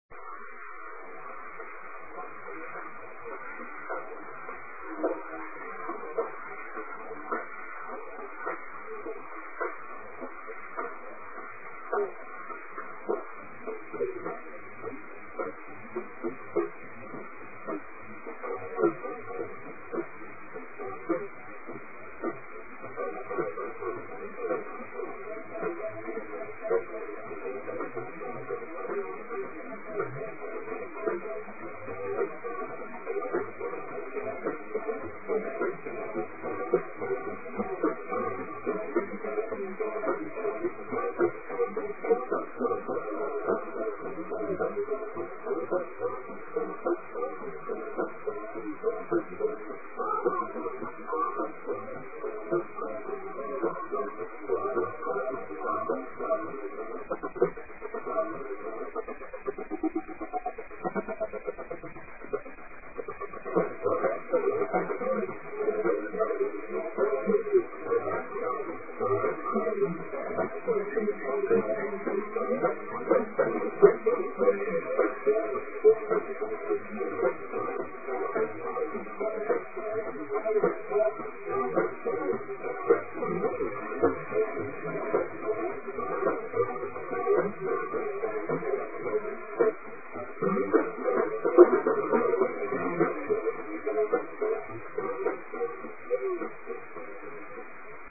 La pr�sente version de ce qui restera invinciblement le premier morceau de rock n' roll propos�e ici en exclusivit� mondiale, est difficile � dater exactement.
premier remix connu du premier morceau de rock de l'histoire humaine
en backing vocals